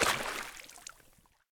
footsteps
shallow-water-04.ogg